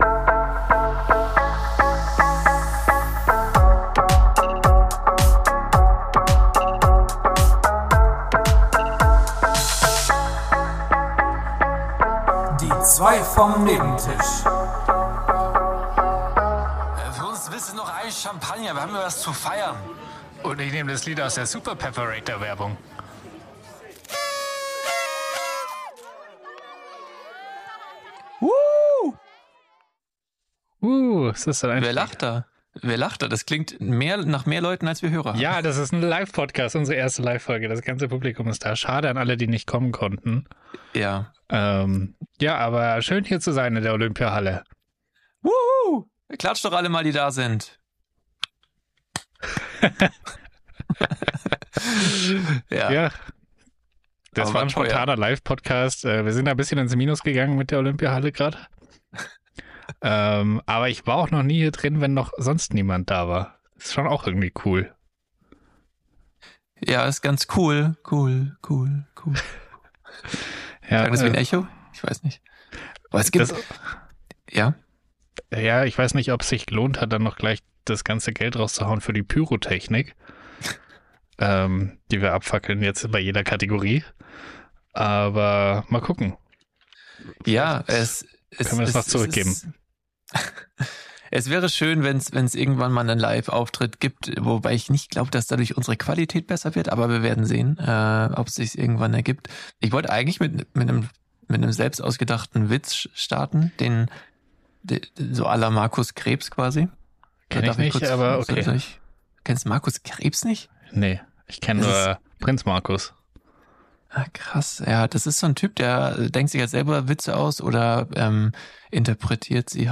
Nein, keine alten Schnipsel, die ihr bereits kennt, sondern live und unzensiert prügeln wir hier eine nach der anderen durch - ein Spaß zum mitraten für die ganz Familie!